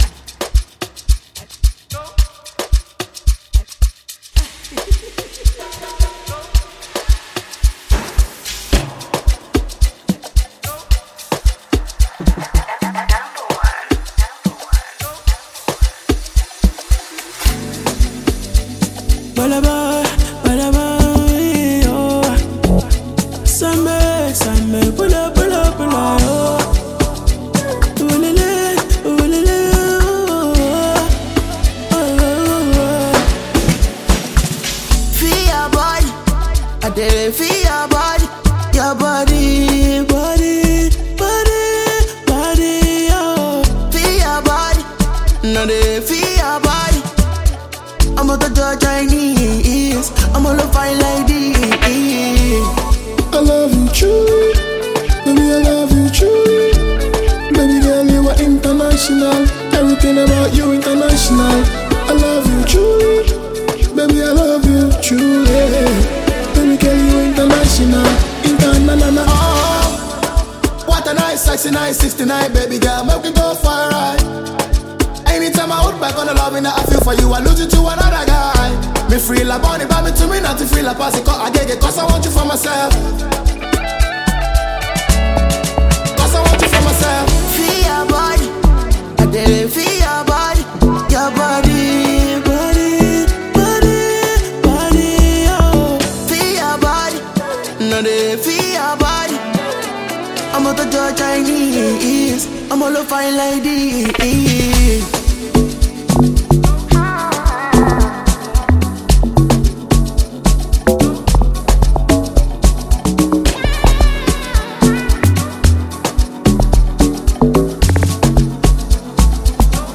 Ghanaian Afropop/Afrobeat singer and songwriter